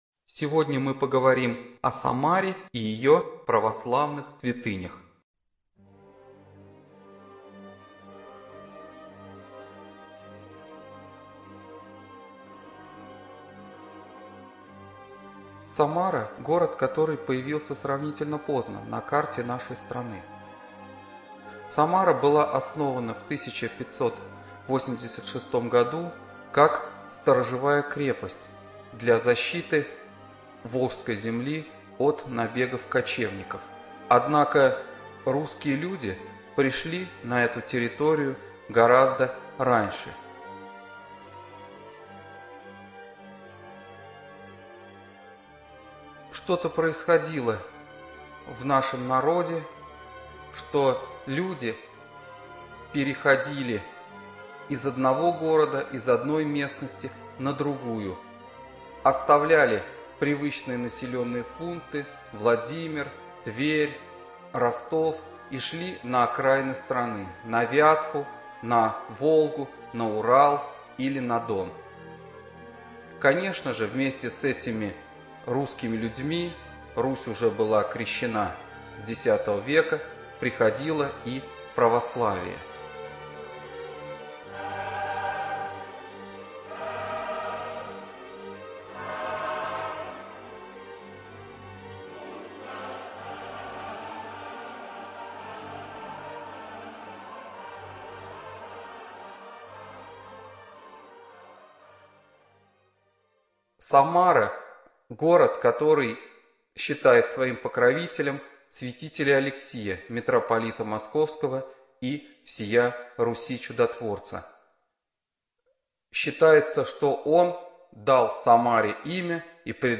Студия звукозаписиСамарская областная библиотека для слепых